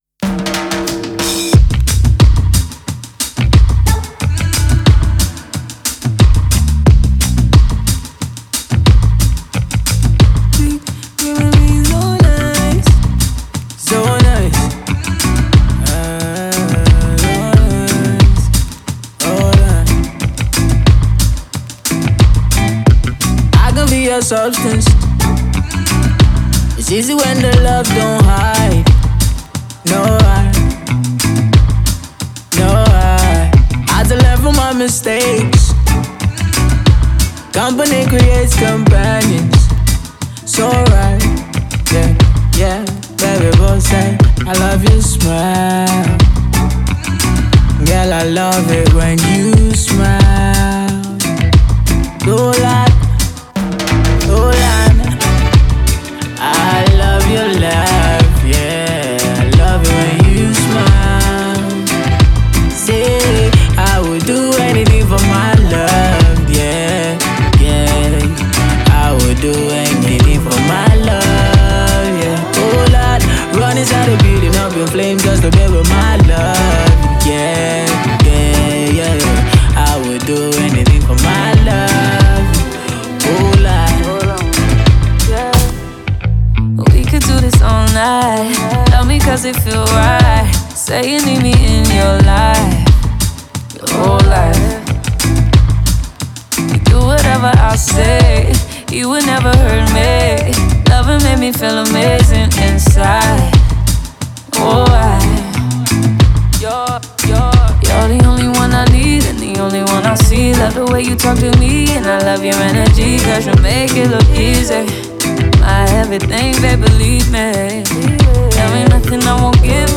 MusicNigeria Music